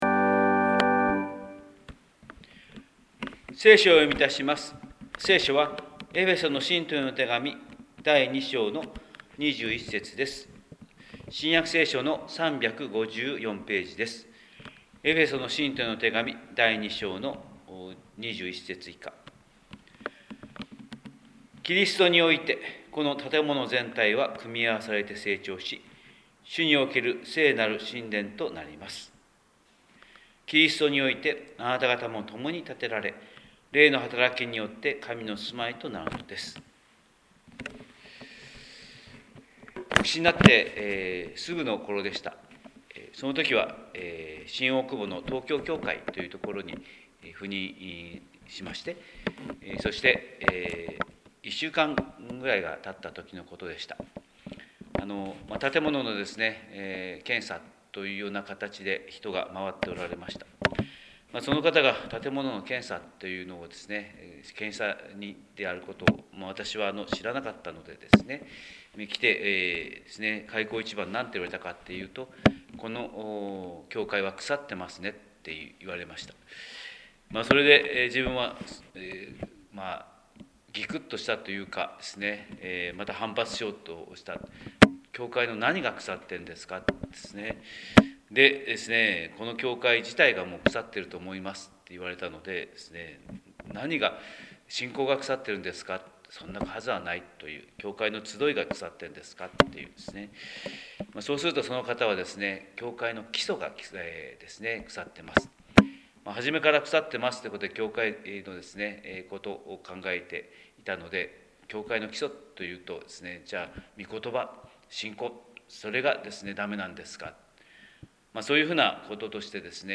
神様の色鉛筆（音声説教）: 広島教会朝礼拝250526
広島教会朝礼拝250526